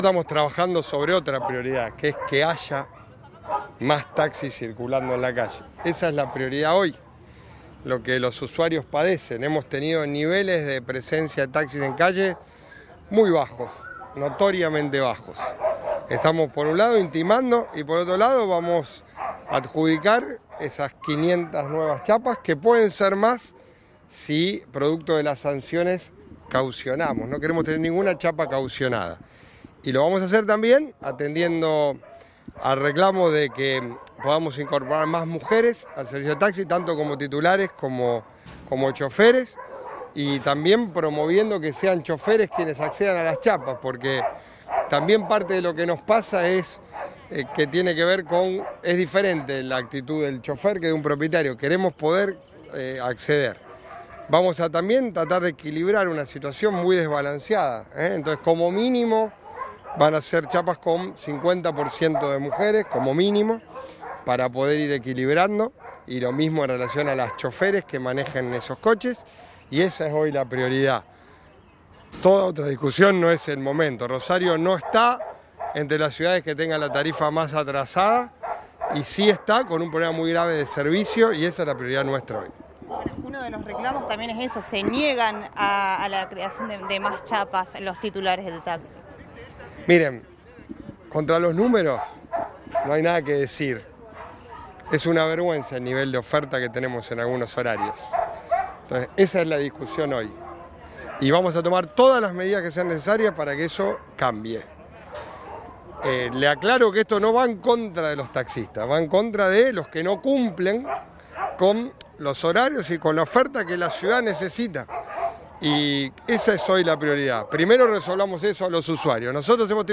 El intendente de la ciudad, Pablo Javkin, negó que pudiera darse un nuevo aumento en la tarifa de taxis. En un evento por el comienzo de traslado de animales del IMUSA al nuevo Centro de Adopción Municipal, el mandatario dio una conferencia de prensa y fue consultado por el reclamo de los titulares de chapas en el Concejo.